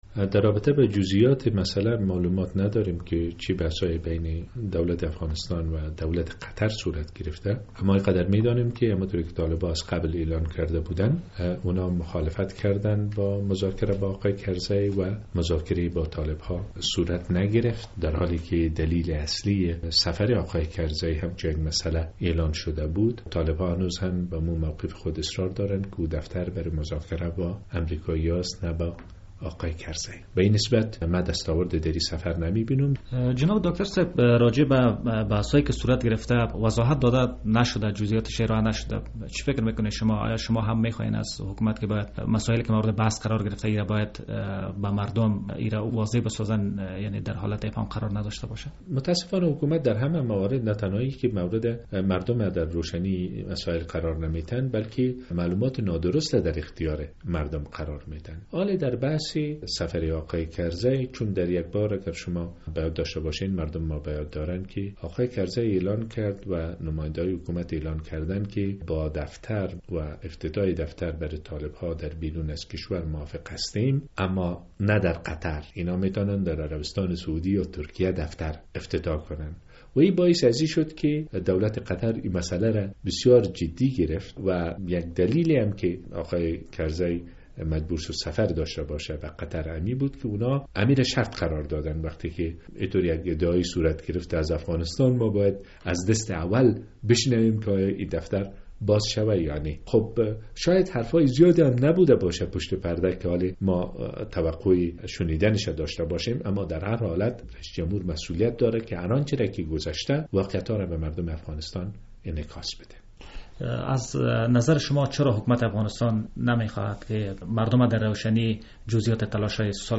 رهبر ایتلاف ملی افغانستان سفر اخیر رییس جمهور کرزی به قطر را بی دستاورد می خواند. داکتر عبدالله، عبدالله این مساله را امروز «سه شنبه» در صحبت اختصاصی با رادیو آزادی بیان کرد...